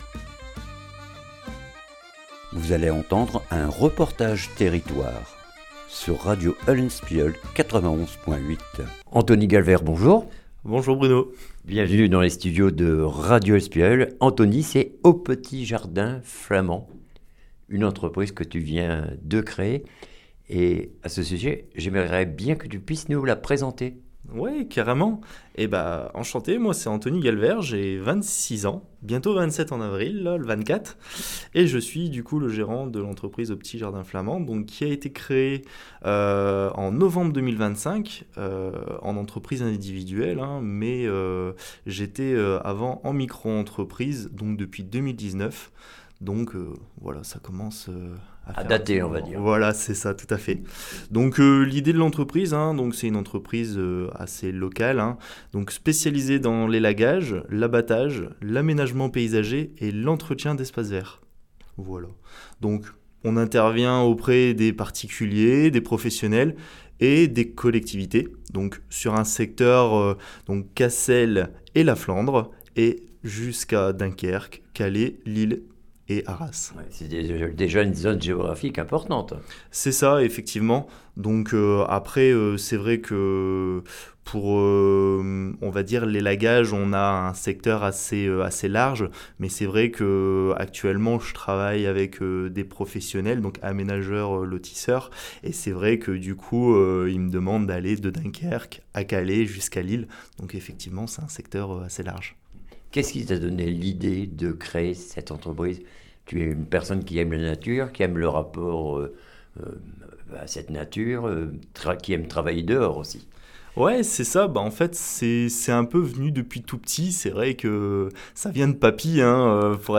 REPORTAGE TERRITOIRE AU PETIT JARDIN FLAMAND CASSEL